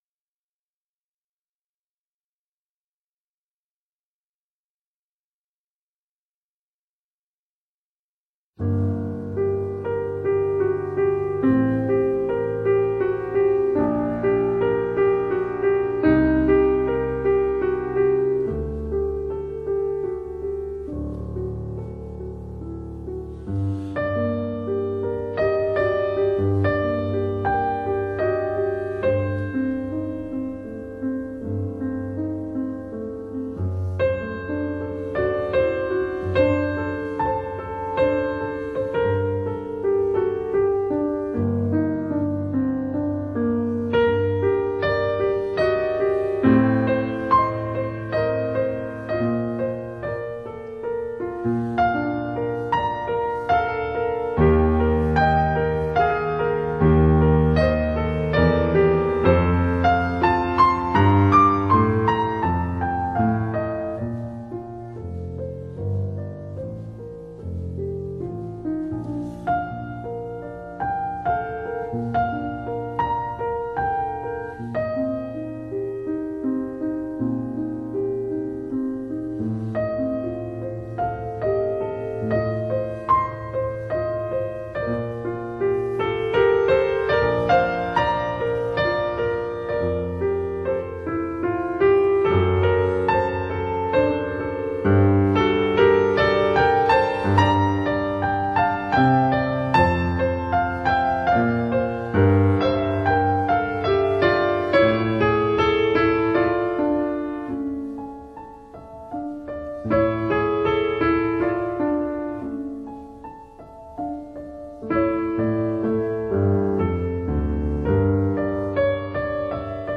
Siciliana
G Major